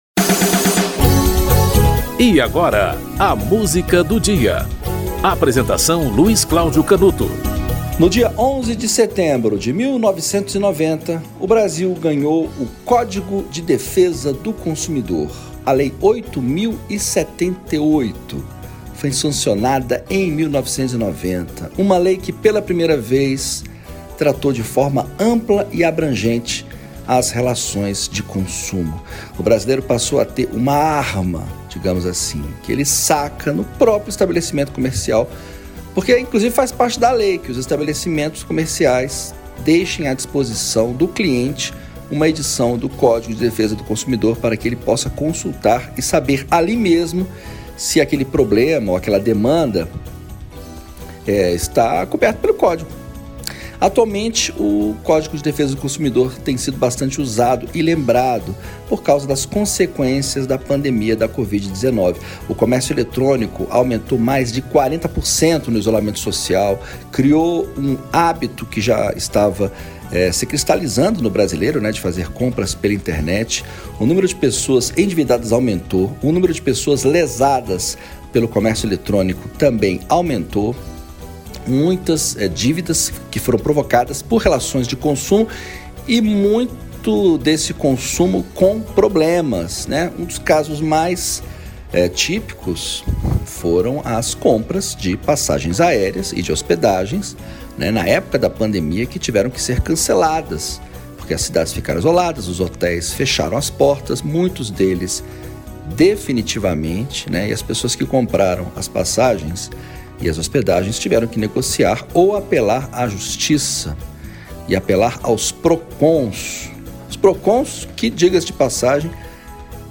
Elza Soares - Paródia do Consumidor (Nei Lopes e Wilson Moreira)